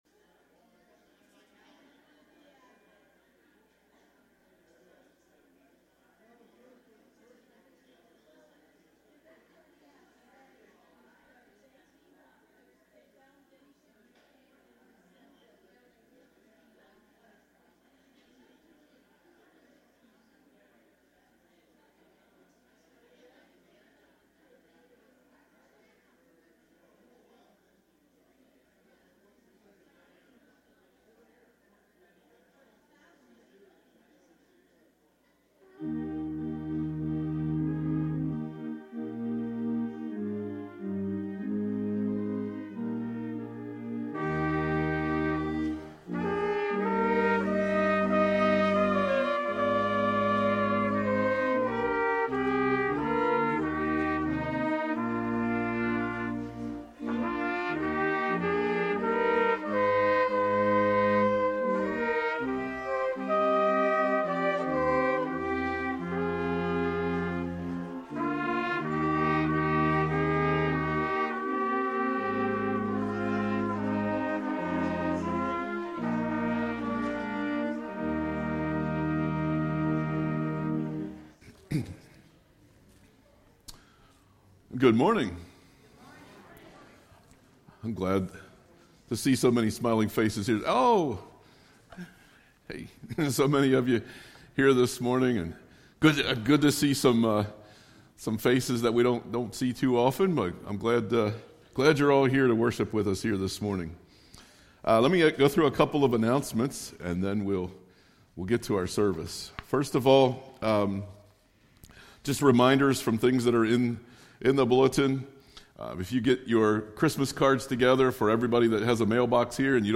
Sunday Worship December 1, 2024